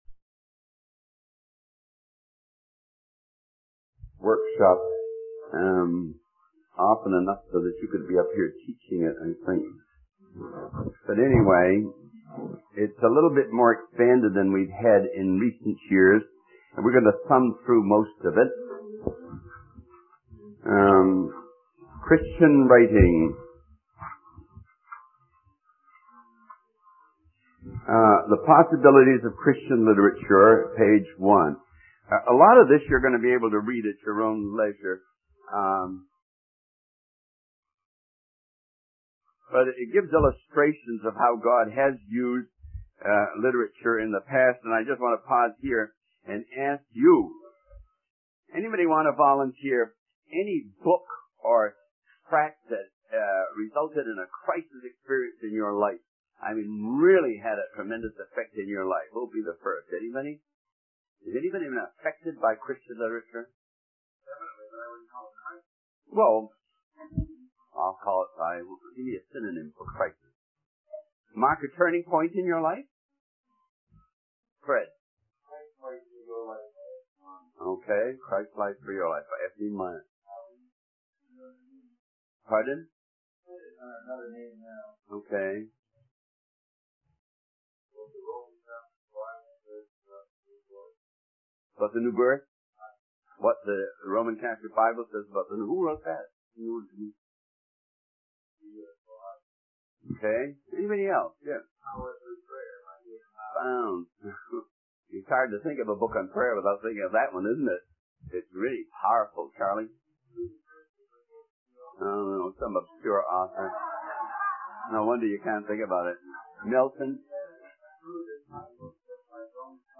The video is a sermon on the preaching of the word of God. It emphasizes the importance of using short sentences and words, varying sentence patterns, and incorporating human interest to make the message more engaging.